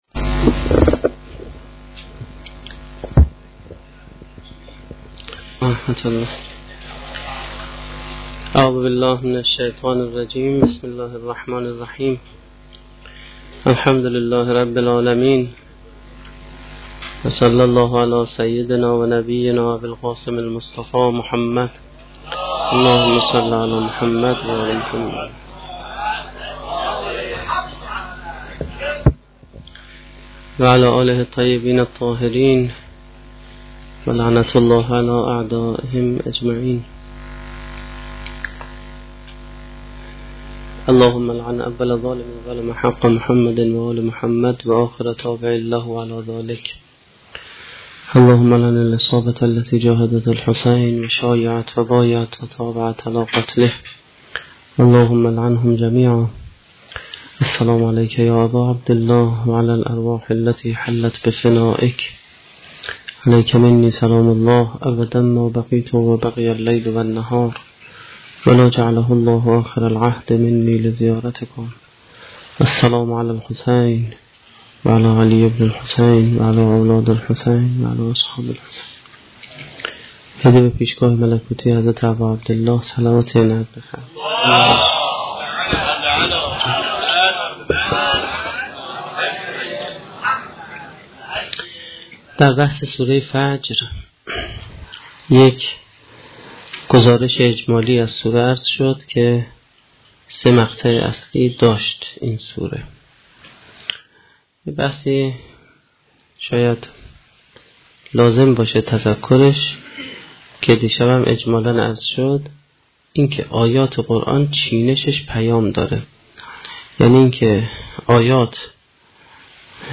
سخنرانی ششمین شب دهه محرم1435-1392